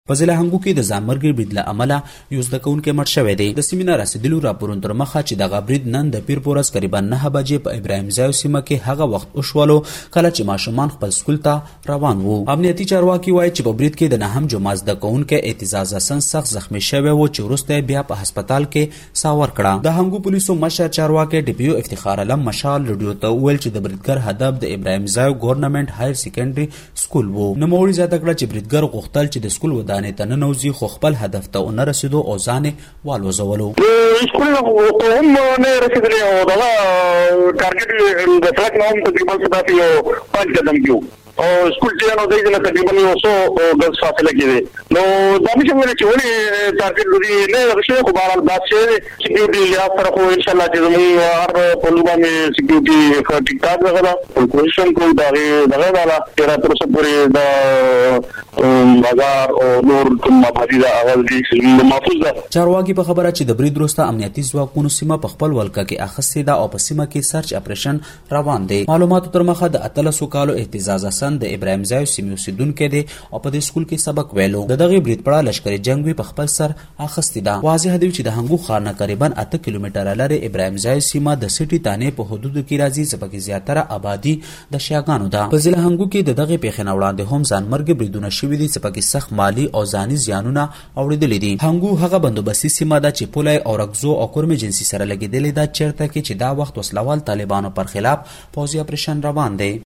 په هنګو کې د شوي ځانمرګې برید په اړه رپوټ دلته واوری.MP3